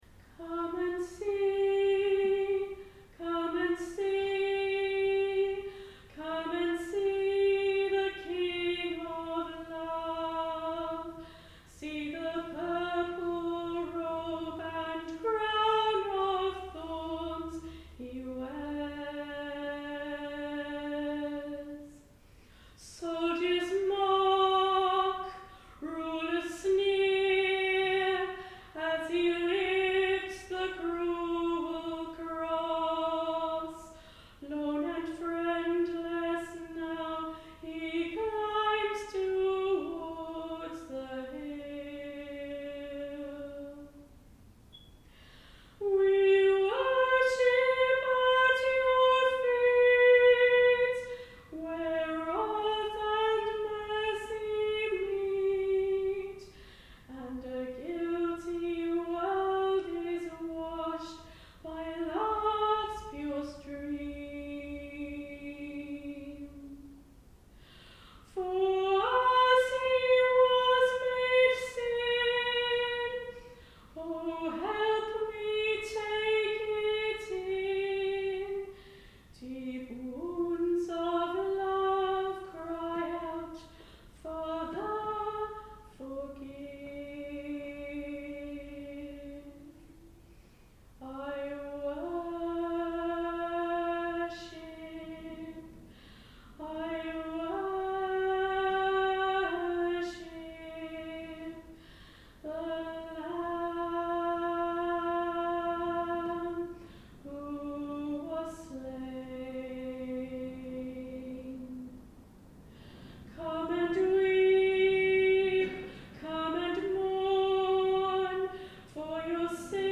Come and See by Graham Kendrick, my second solo from a Good Friday service on 18 April 2025